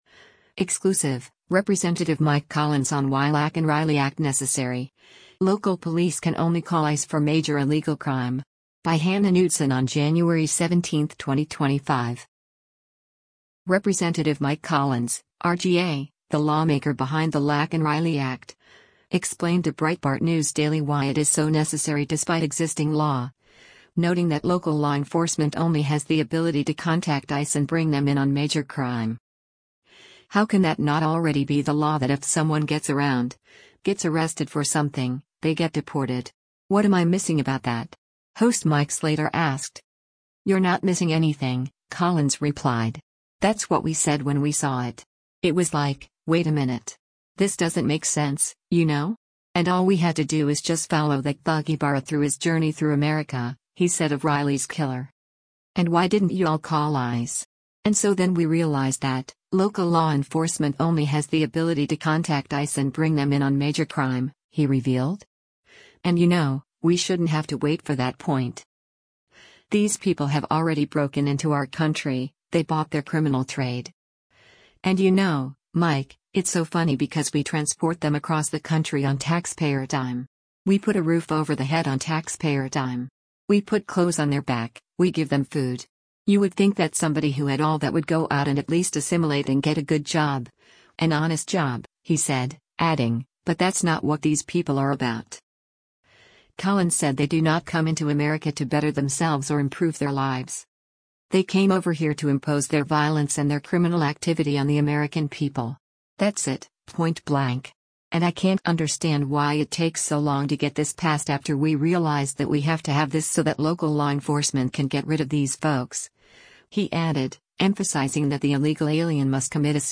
Breitbart News Daily airs on SiriusXM Patriot 125 from 6:00 a.m. to 9:00 a.m. Eastern.